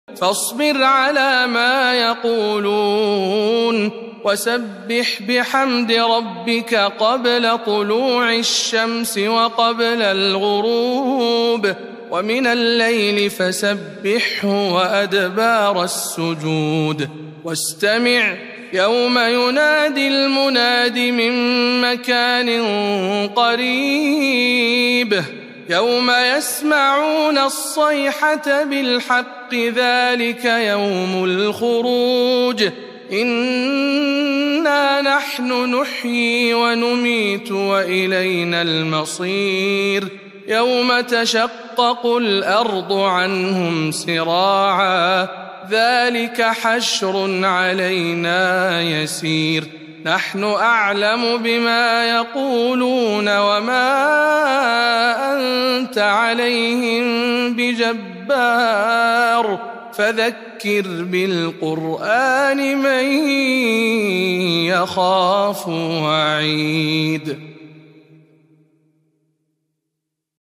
تلاوة مميزة من سورة ق